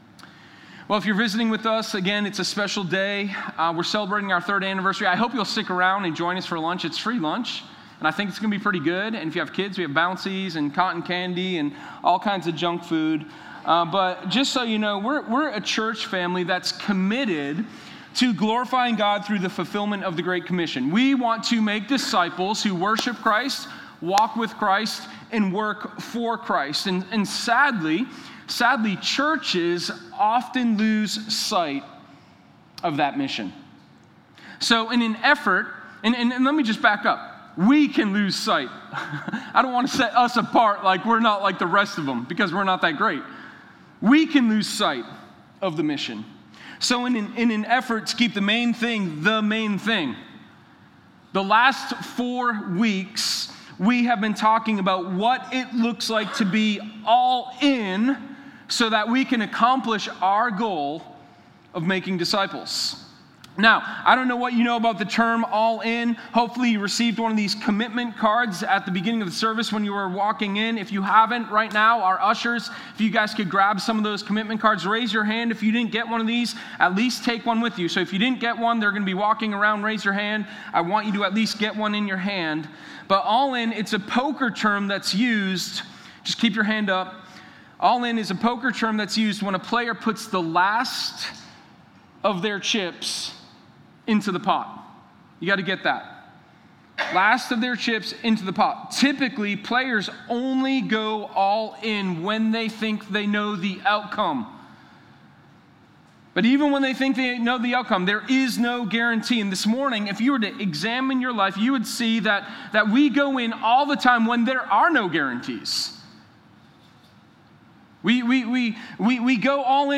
Sermon1007_Serve.mp3